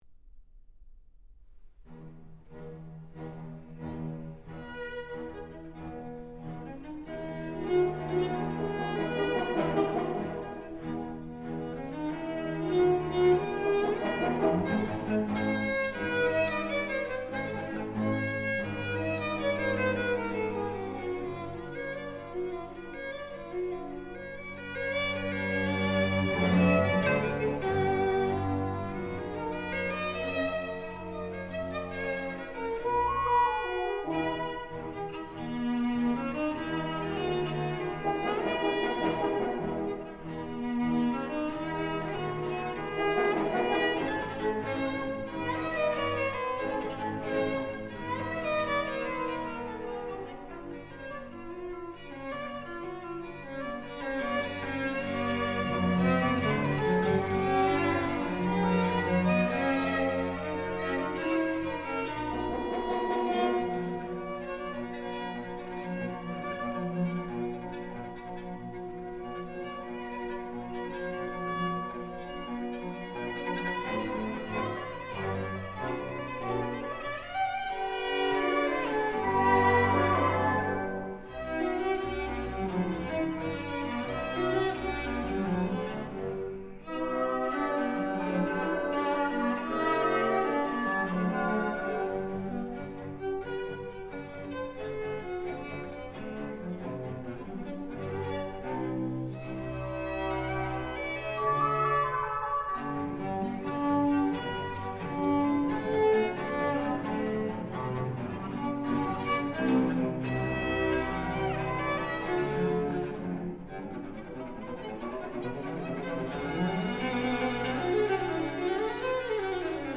deux violoncelles et orchestre - 3 Moderato